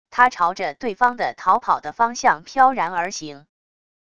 他朝着对方的逃跑的方向飘然而行wav音频